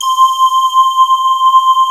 STEAM C5.wav